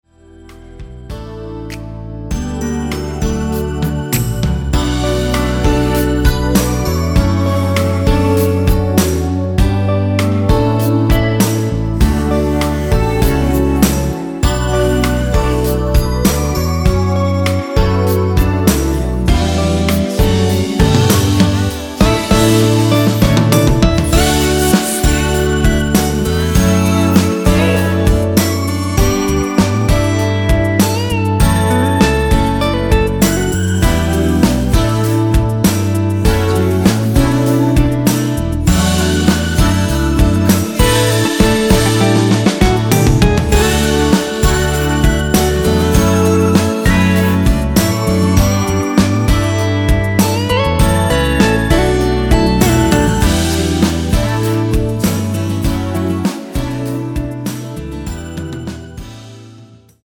원키 코러스 포함된 MR입니다.
D
앞부분30초, 뒷부분30초씩 편집해서 올려 드리고 있습니다.
중간에 음이 끈어지고 다시 나오는 이유는